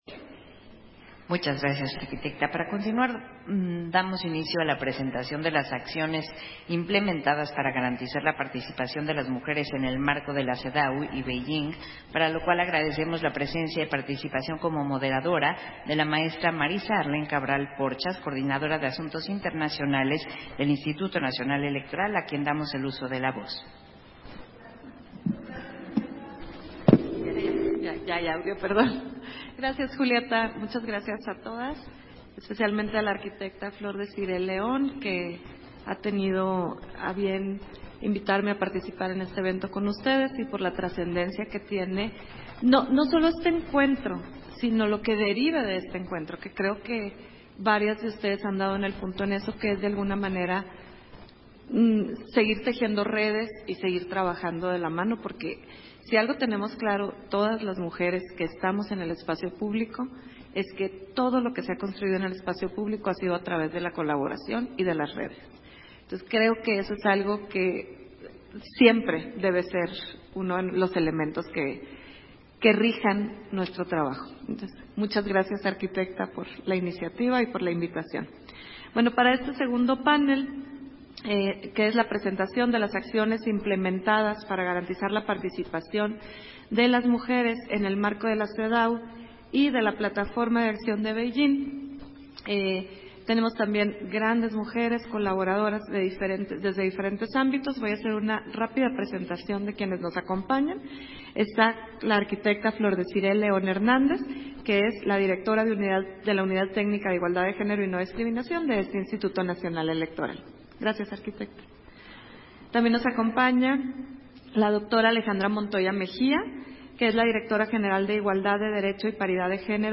Versión estenográfica del panel 2: Presentación de las acciones implementadas para garantizar la participación de las mujeres en el marco de la CEDAW y Beijing, encuentro entre expertas e instituciones para dar al X informe de México ante el Comité para la Eliminación de todas las formas de discriminación contra la mujer de la declaración y la plataforma de acción de Beijing